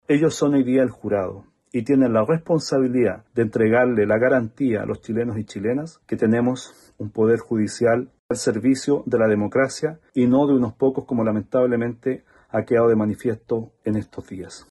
Eric Aedo, otro de los diputados de la terna que completa, Daniel Manoucheri, pasó toda la responsabilidad al Senado recordando que, ante todo, serán jueces esta jornada.